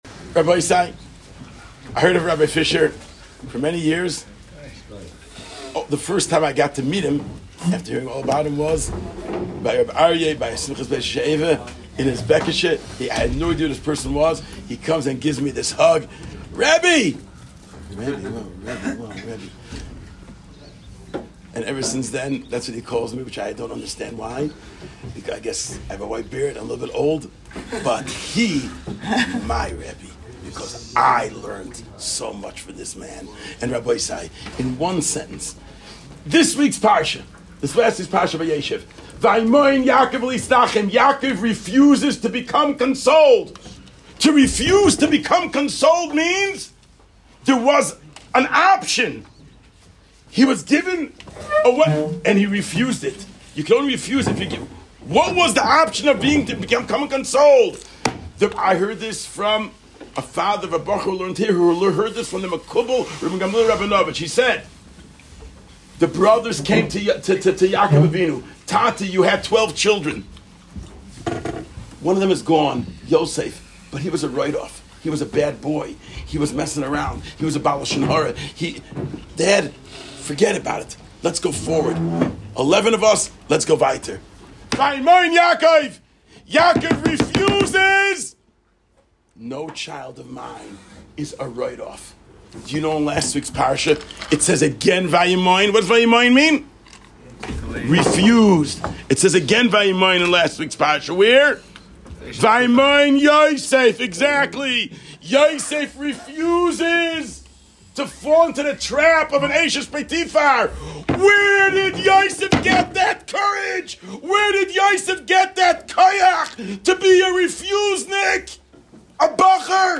Shiur @ Imrei Binah